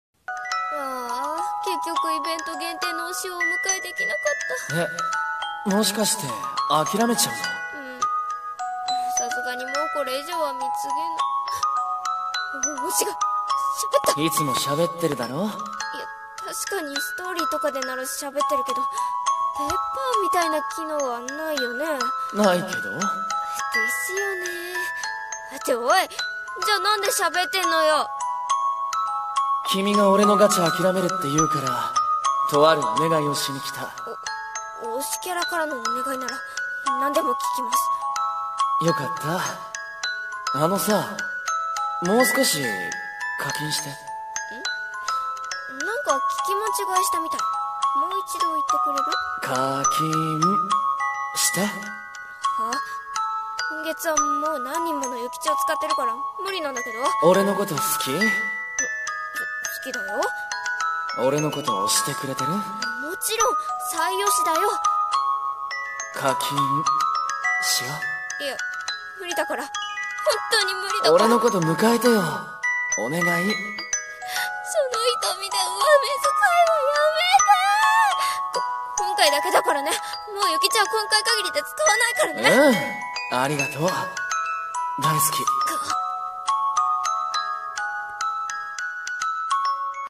【声劇】……課金して？